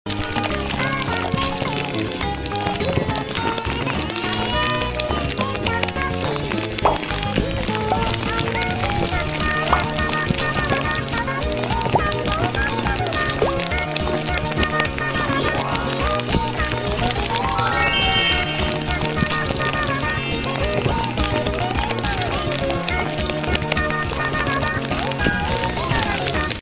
Free emoji cat sounds sound effects free download